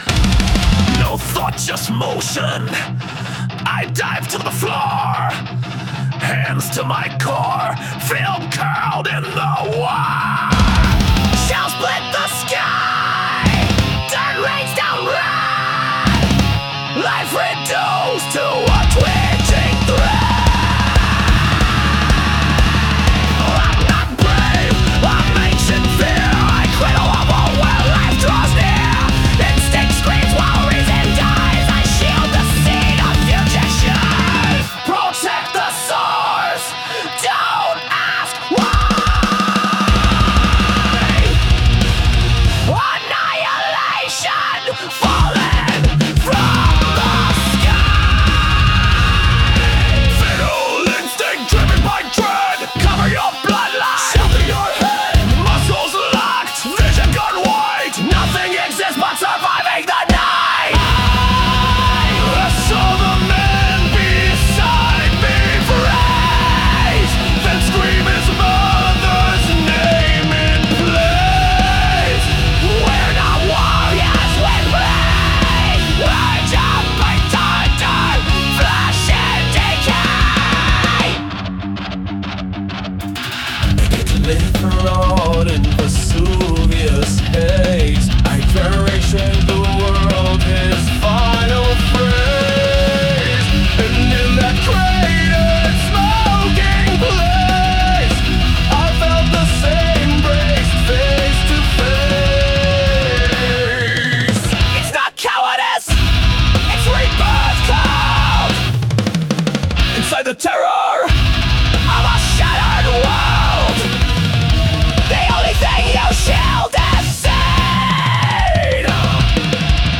the crushing force of Thanatonic Metal